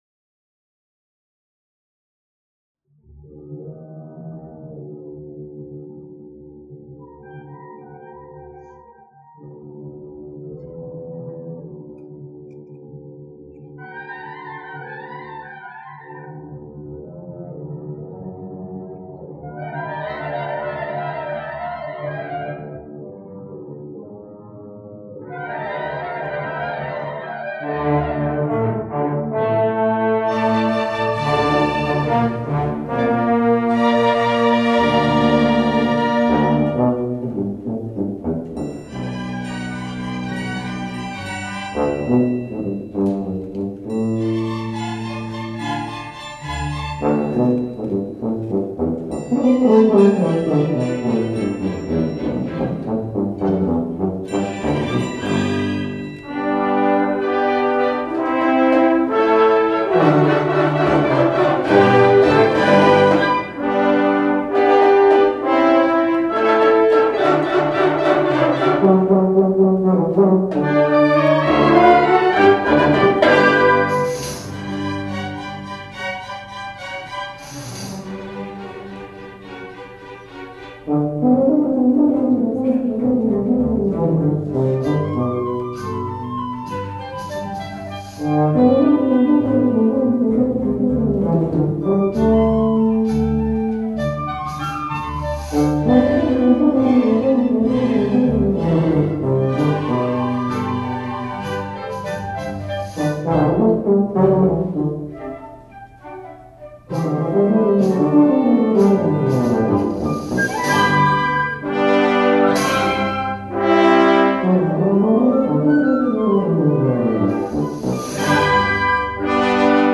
for Tuba and Orchestra (1995)